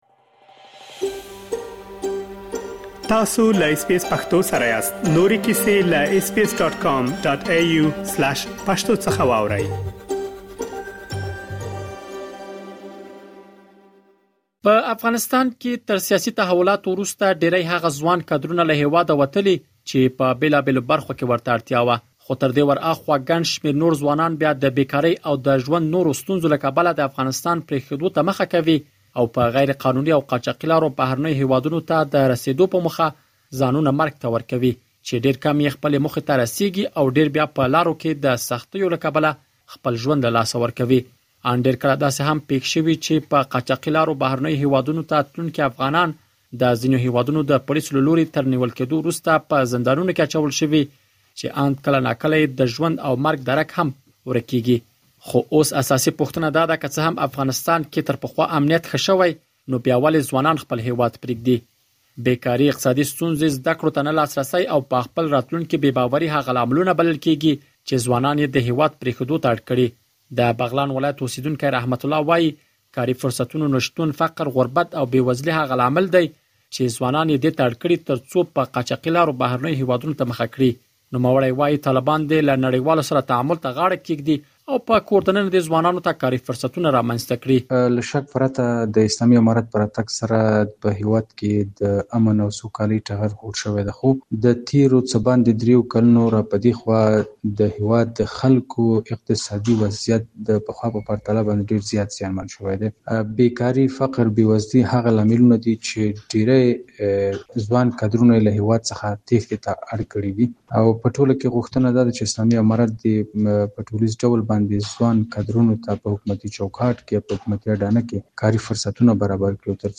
سره له دې چې طالبان او یو شمېر عام افغانان ادعا کوي چې افغانستان کې امنیت ښه شوی، خو بیا هم یو زیات شمېر افغانان غیر قانوني کدوالۍ ته مخه کوي. دا چې یو شمېر افغانان ولې هېواد پرېږدي، په دې اړه لا ډېر معلومات په رپوټ کې اورېدلی شئ.